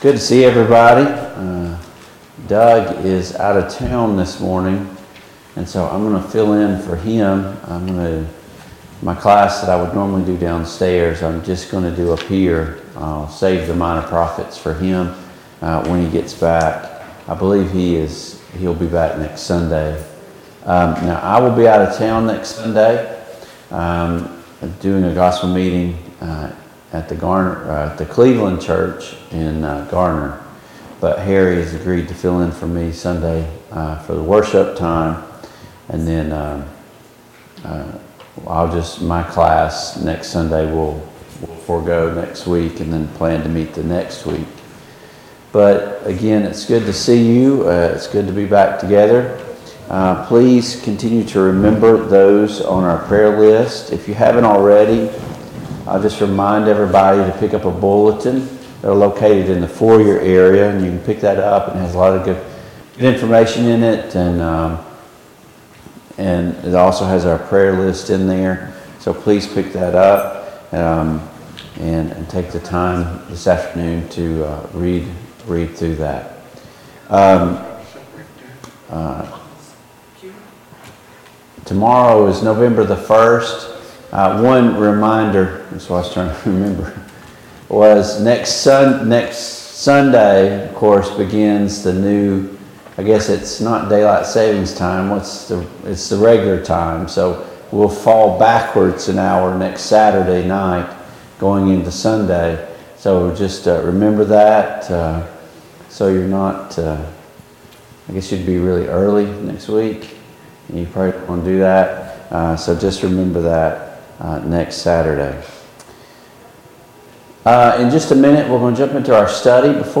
Service Type: Sunday Morning Bible Class Topics: Authority , Biblical Interpretation